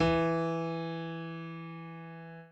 admin-fishpot/b_pianochord_v100l1-2o4e.ogg at main